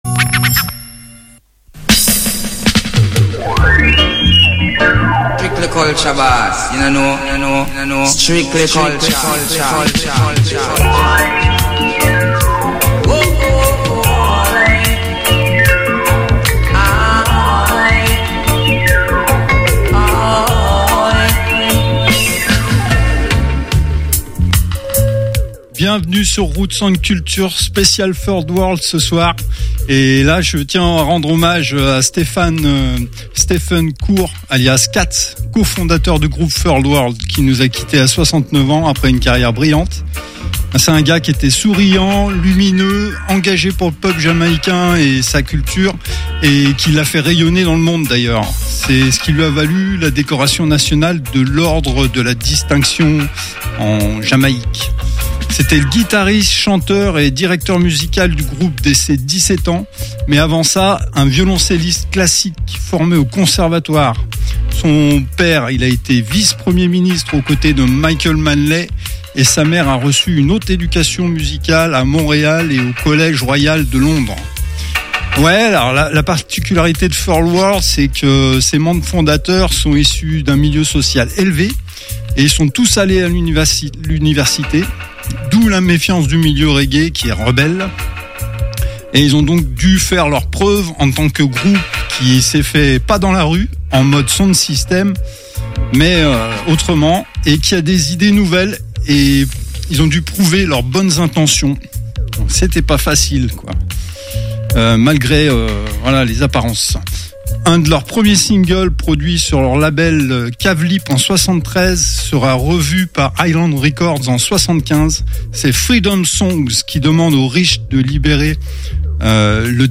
tous les mercredi en live 22-23h